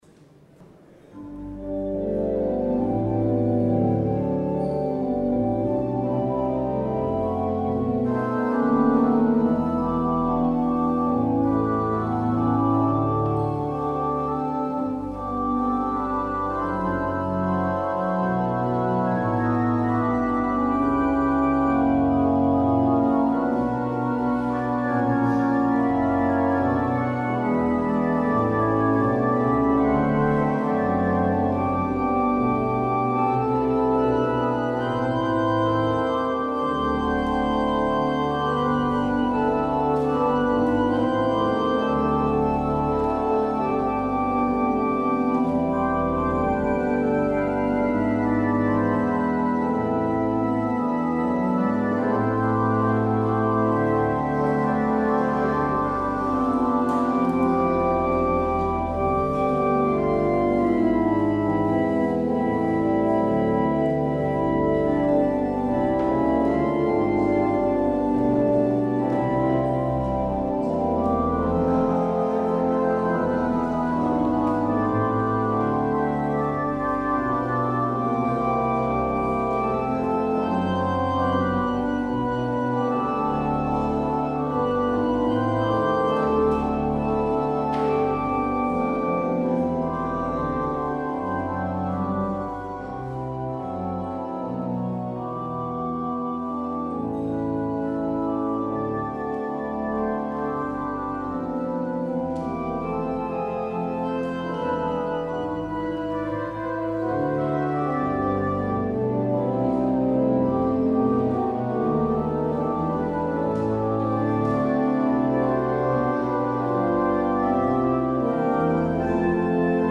Het openingslied is: NLB 840: 1, 2 en 3.